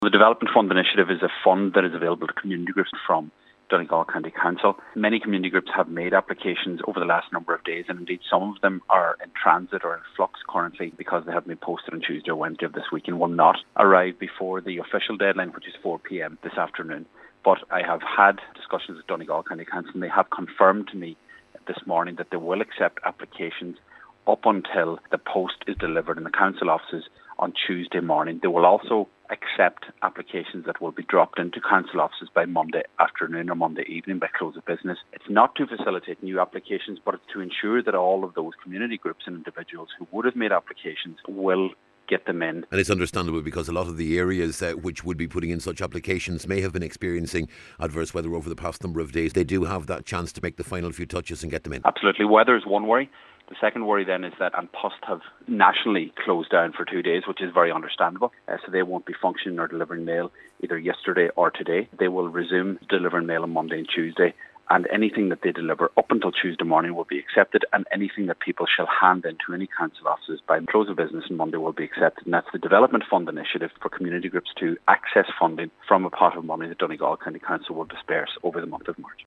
Cllr Seamus O’Domhnaill says it is an important initiative……….